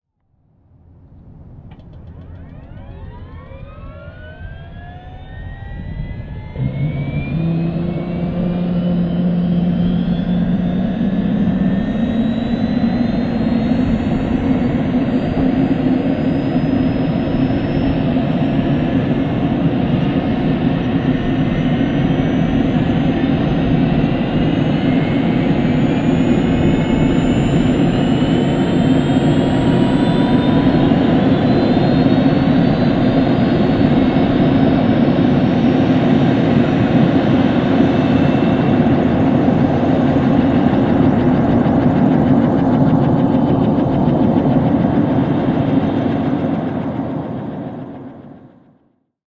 startup_exterior_mono_raw_interior.wav